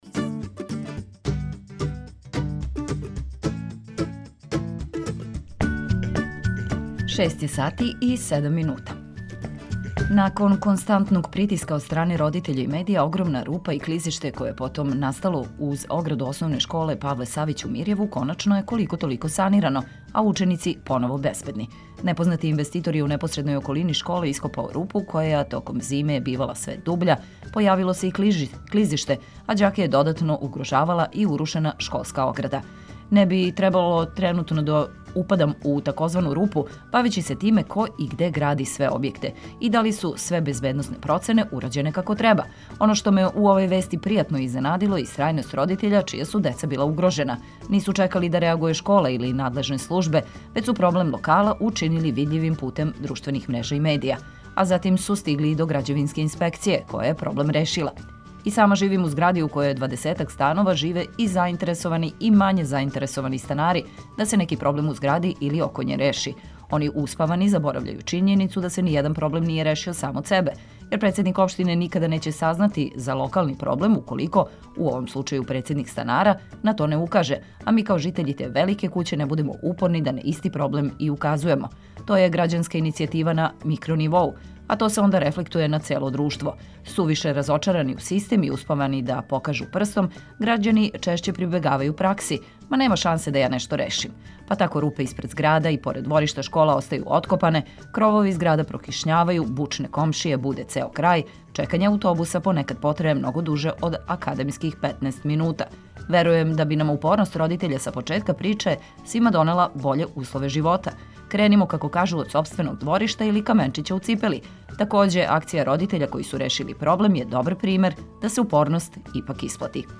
Цртица 202, време, стање на путевима, Културни водич, Добро јутро у два издања, Супер спорт 202, биопрогноза, курс динара и још много информација упаковаћемо у емисију која ће вам обезбедити добру забаву уз велике хитове домаће и стране музике.